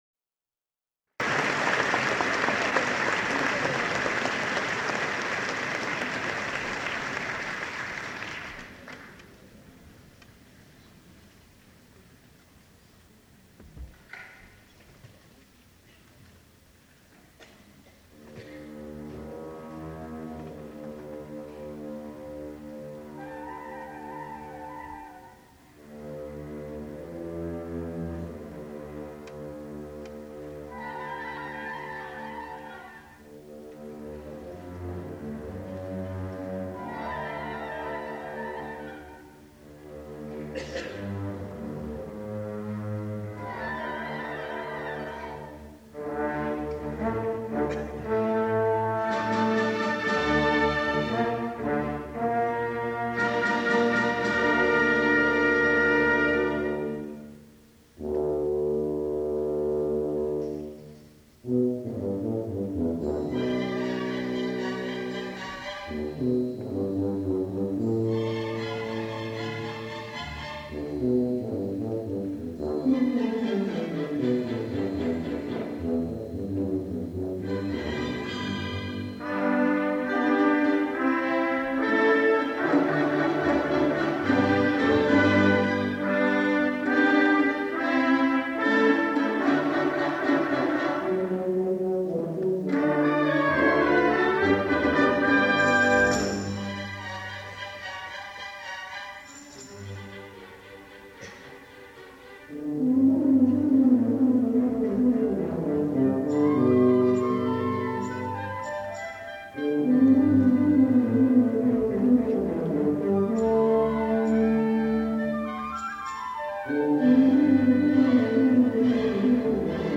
for Tuba and Orchestra (1995)